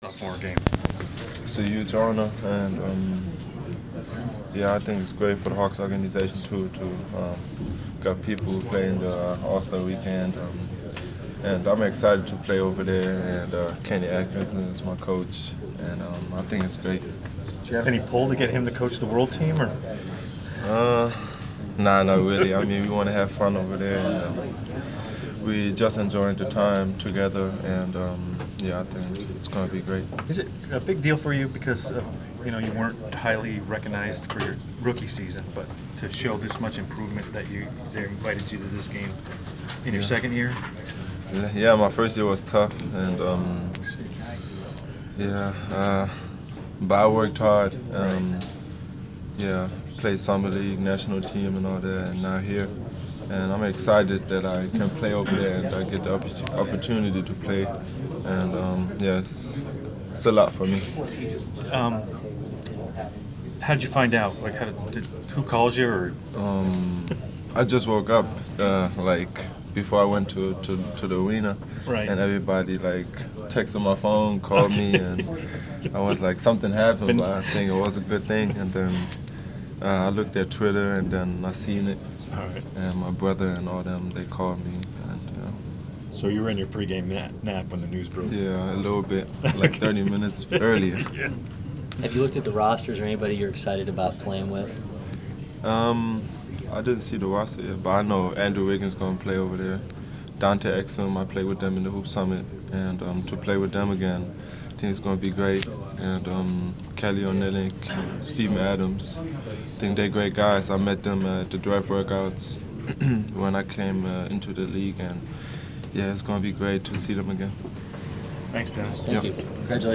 Inside the Inquirer: Pregame interview with Atlanta Hawks’ Dennis Schroder (1/28/15)
We caught up with Atlanta Hawks’ guard Dennis Schroder before his team’s home game against the Brooklyn Nets on Jan. 28. Topics included his inclusion into the BBVA Compass Rising Stars Challenge on the World Team and his overall development as a player in his second NBA season.